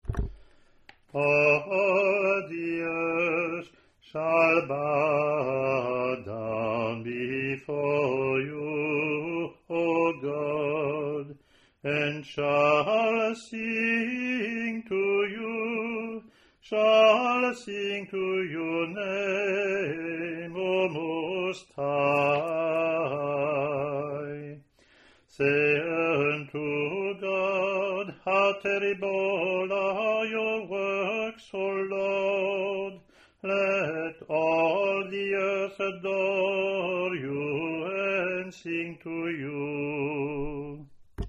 English antiphon – English verse
ot02-introit-eng-pw.mp3